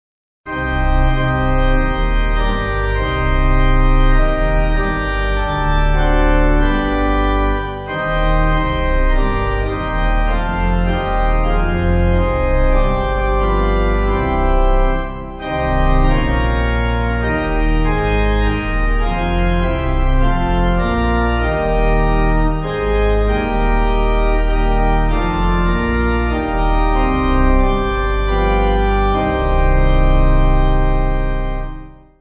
Organ
(CM)   4/Cm